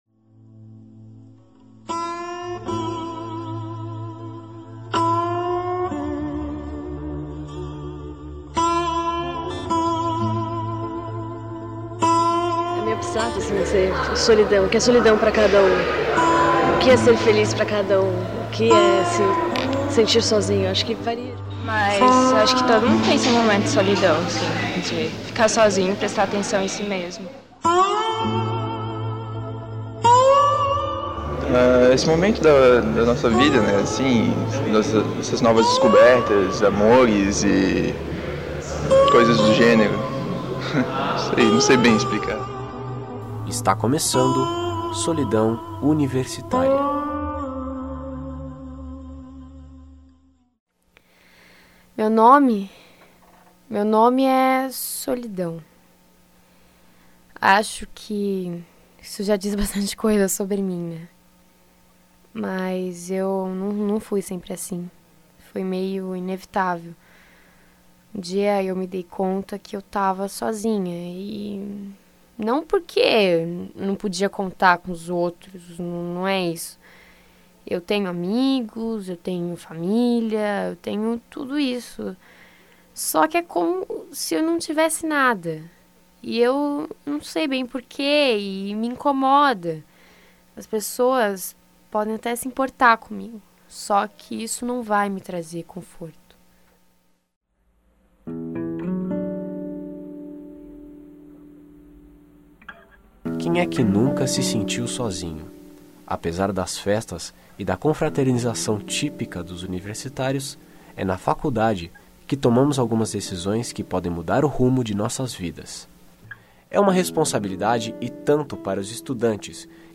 O programa tenta explicar a solidão entre estudantes do ensino superior, com entrevistas e depoimentos de universitários e especialistas na área.
Documentário